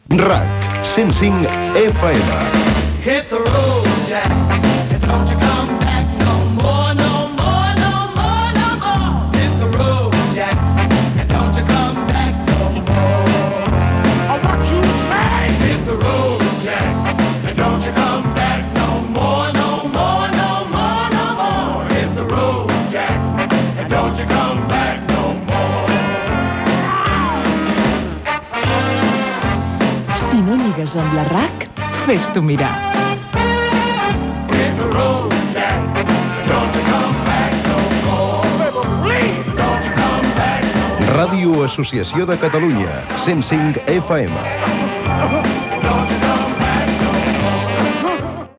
Indicatiu de l'emissora